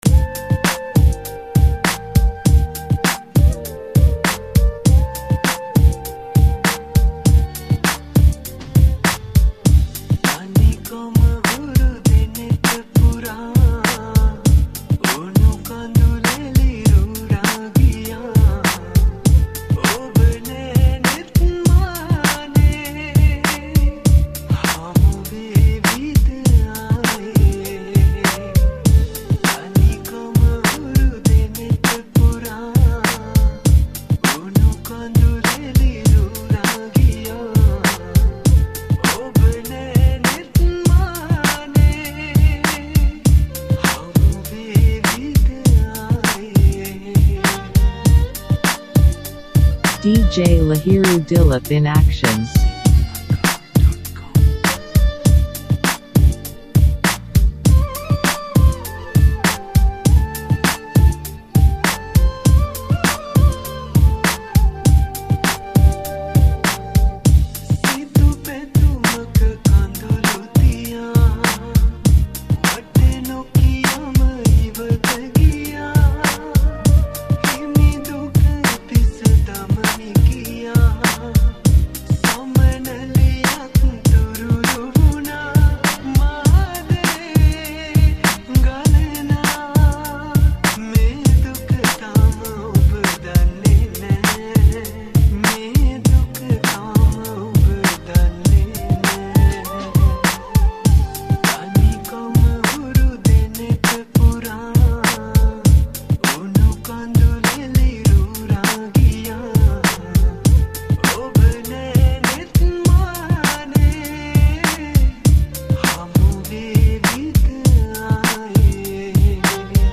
High quality Sri Lankan remix MP3 (10.1).
Remix